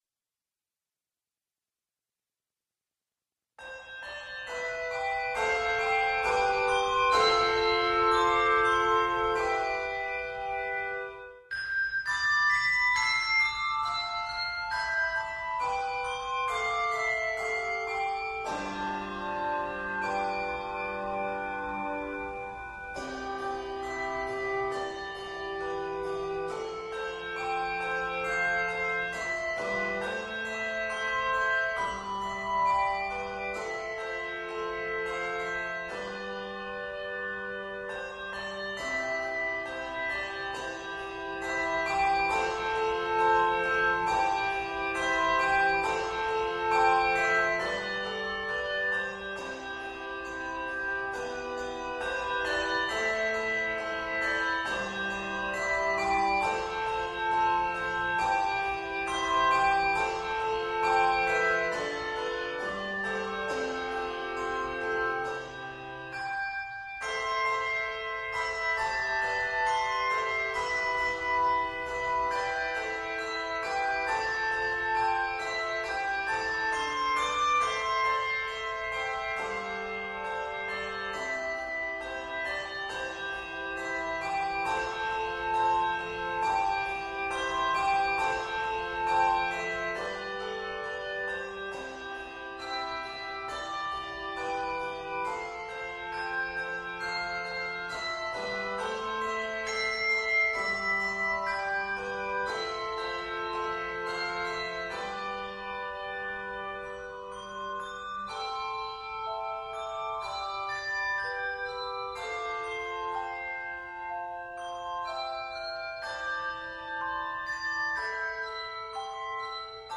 Two Irish folk tunes